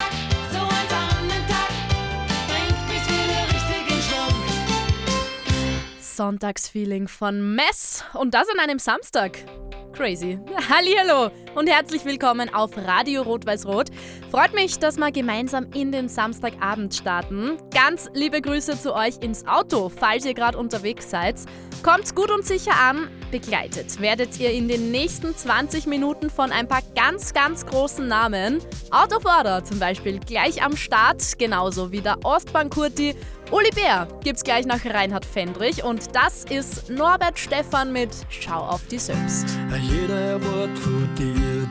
Radio Rot Weiss Rot Moderation